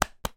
Звуки человека
Человек дважды хлопает по животу ладонью